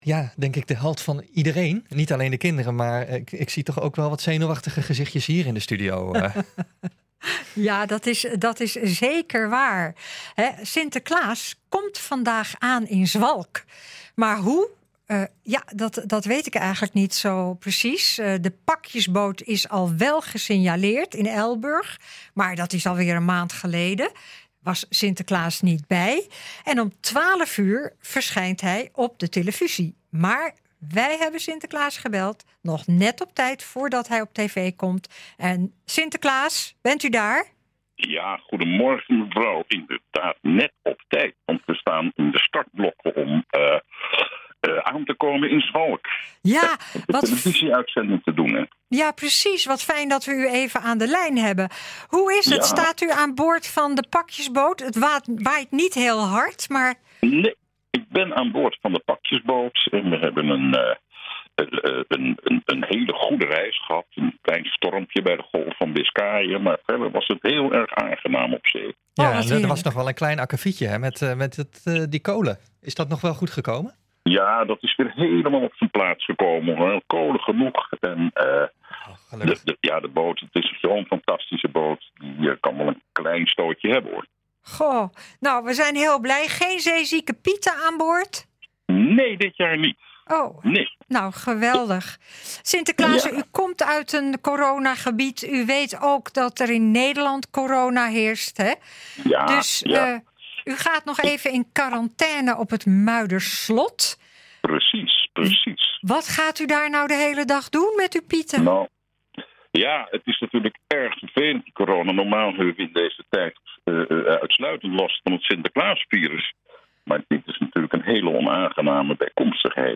NH Gooi is de streekomroep voor Hilversum, Huizen, Blaricum, Eemnes en Laren.
een-exclusief-interview-met-sinterklaas.mp3